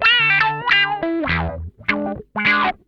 CRUNCH LICK3.wav